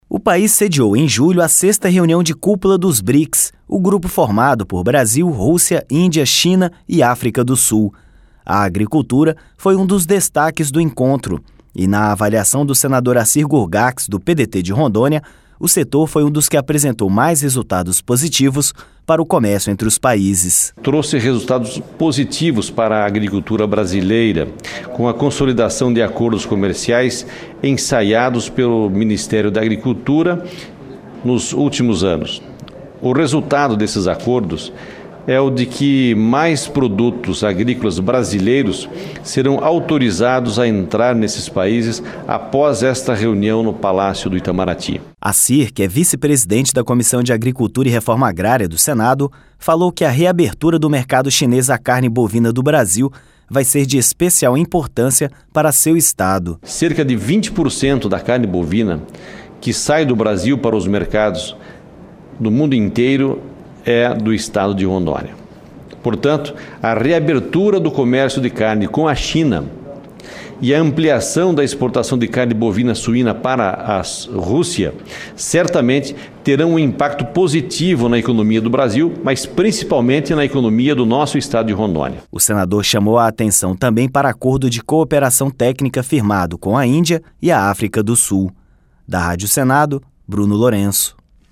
Gurgacz comemora entrada de produtos agrícolas do Brasil nos países dos Brics — Rádio Senado